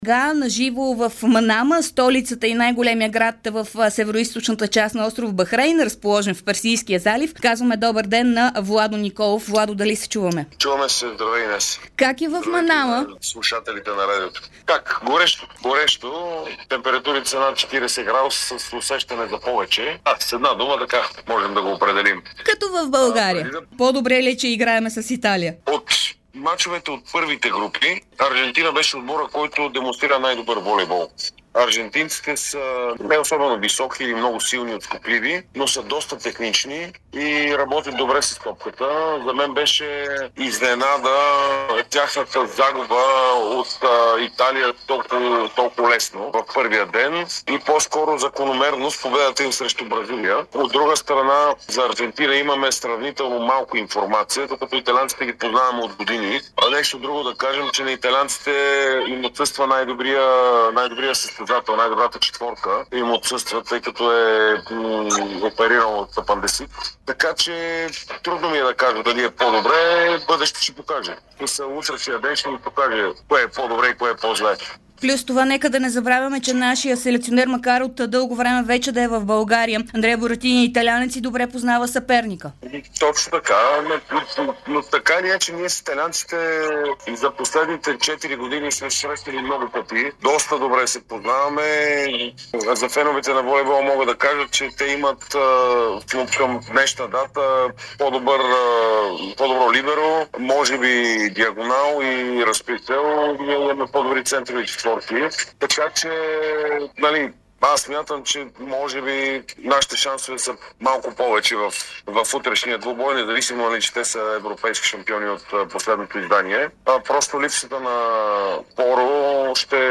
Волейболната ни легенда Владо Николов говори за Дарик радио на живо от Бахрен преди полуфинала на Световното първенство до 21 години, в който „лъвовете“ ще се изправят срещу Италия в събота.